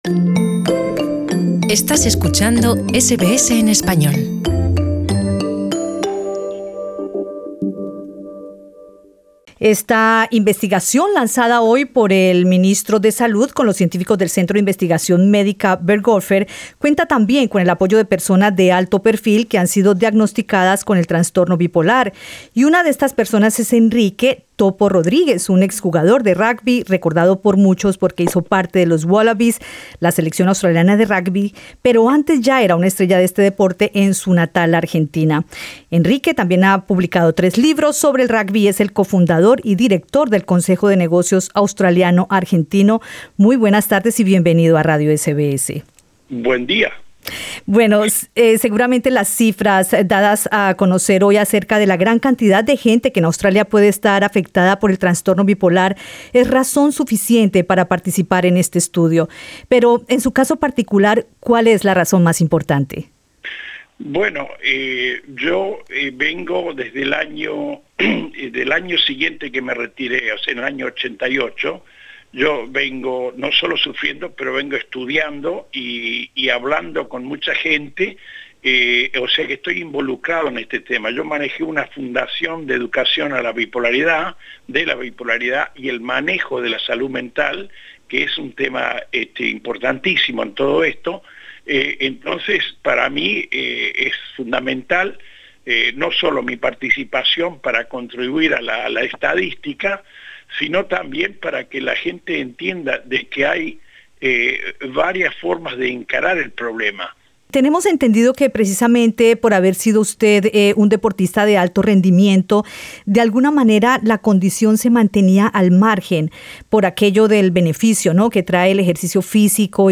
Escucha el podcast con la entrevista a Enrique Topo Rodríguez, en la que él narra de qué manera supo que el trastorno bipolar era parte de su vida, y la lucha que ha librado en las últimas décadas.